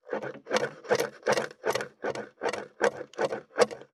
462,切る,包丁,厨房,台所,野菜切る,咀嚼音,ナイフ,調理音,まな板の上,料理,
効果音厨房/台所/レストラン/kitchen食材